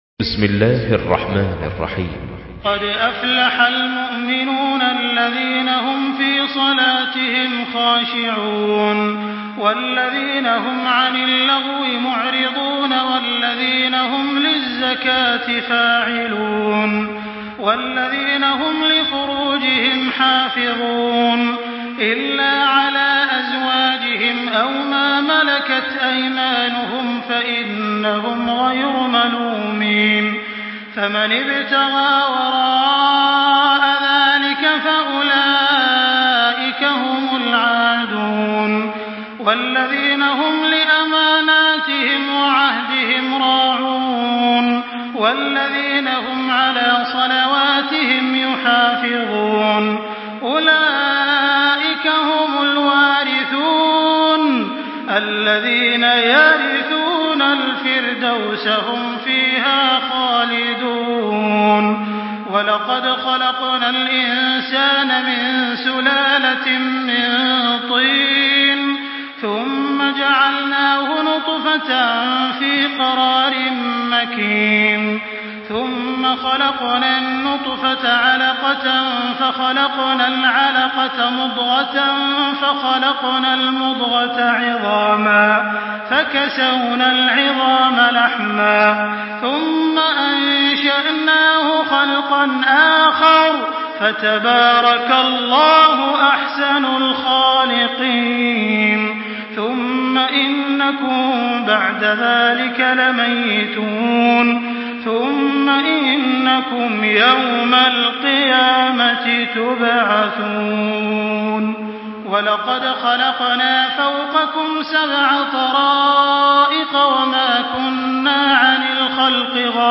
تحميل سورة المؤمنون بصوت تراويح الحرم المكي 1424
مرتل